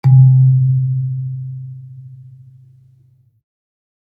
kalimba_bass-C2-pp.wav